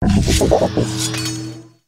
kingambit_ambient.ogg